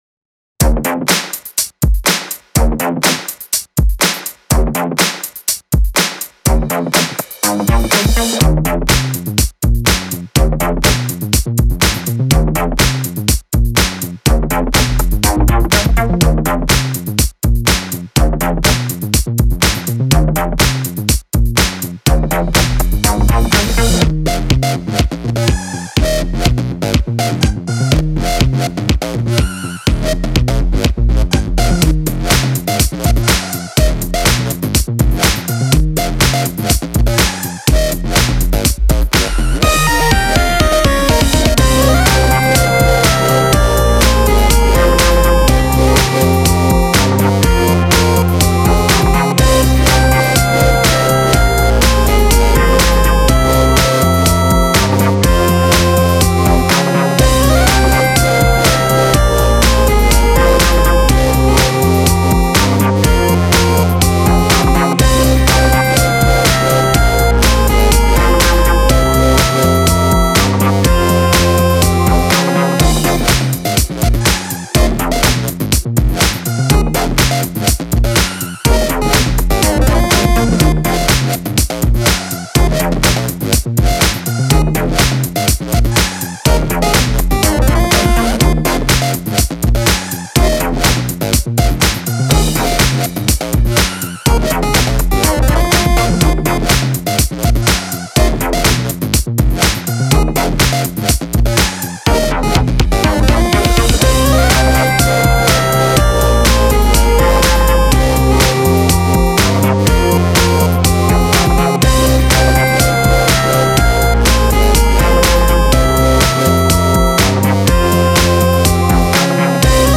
so I busted out this wacky funk track instead..
Love the funk, love that distorted line that kicks in!
funky ending handddd clllaappp awesomeness! happy new year!
Sounds like a party.  Always love your bass sounds.
And love when it all kicks in after a minute too!